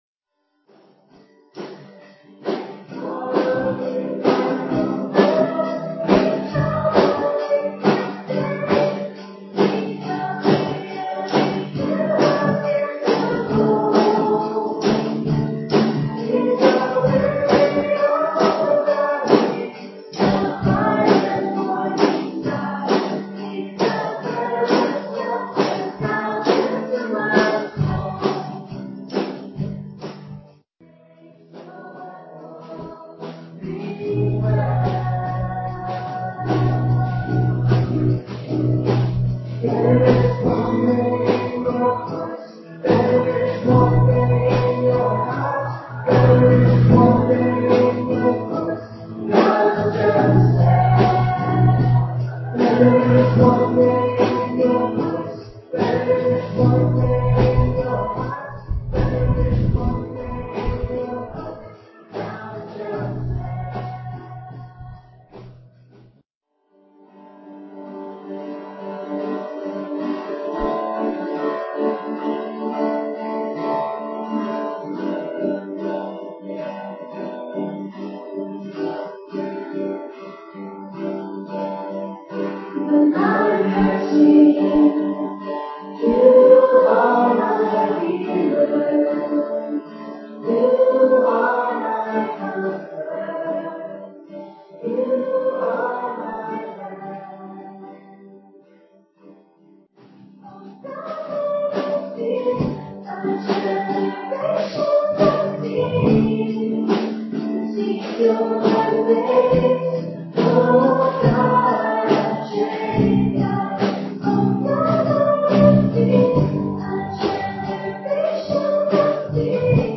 at Ewa Beach Baptist Church